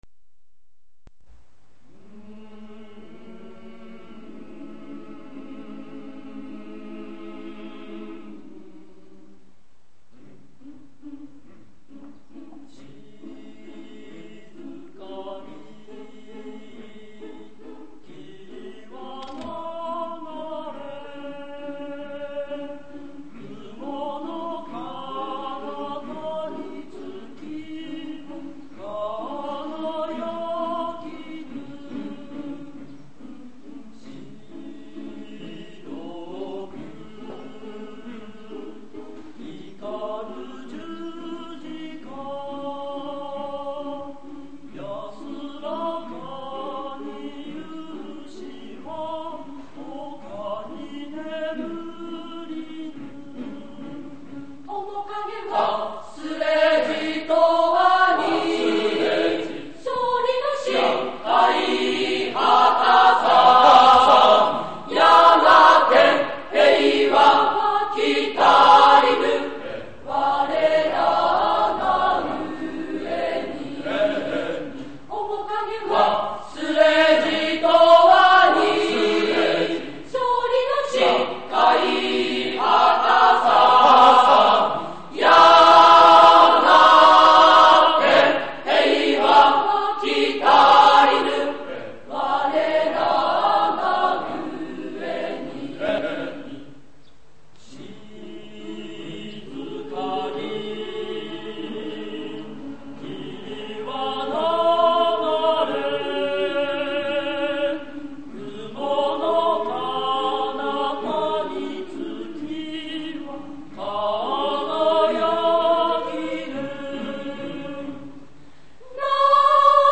巨摩中が解体されてから、３０年余りが経ち、先生により録音され、残されていた合唱テープは
体育館での合唱発表会のライブです。